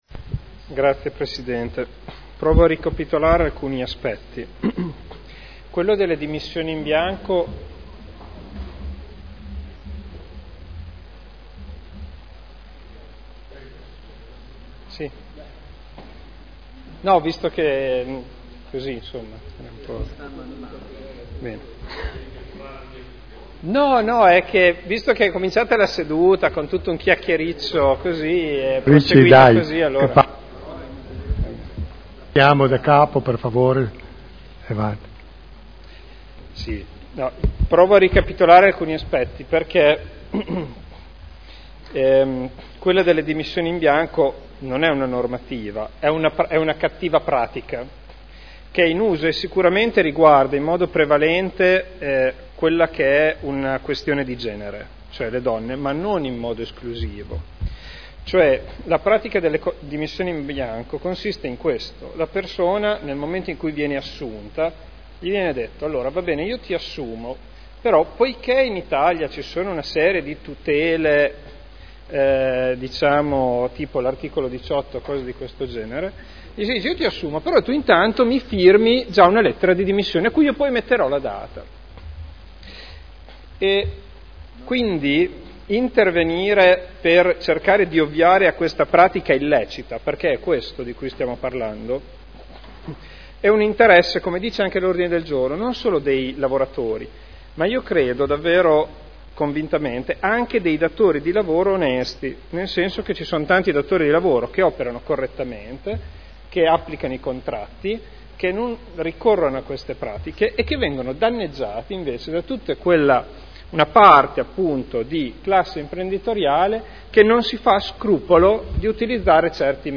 Seduta del 07/05/2012. Dibattito.